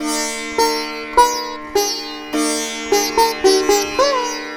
105-SITAR5-R.wav